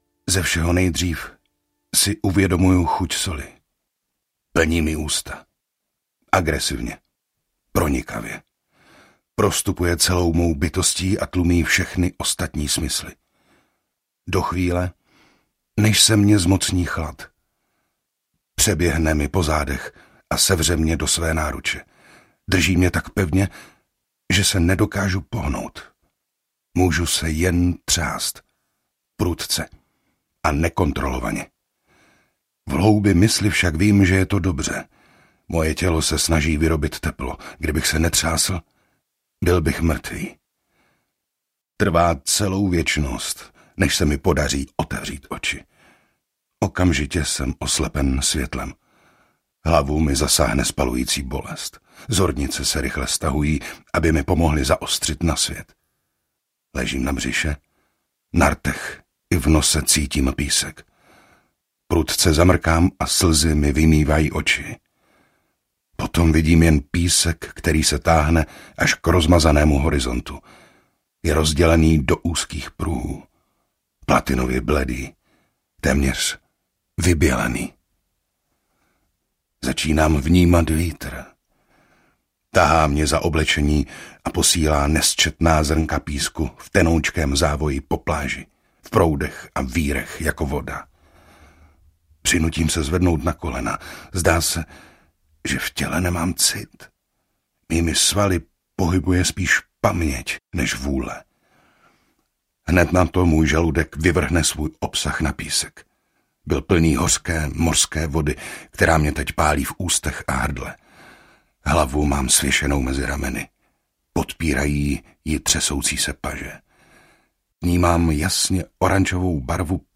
Umrlčí cesta audiokniha
Ukázka z knihy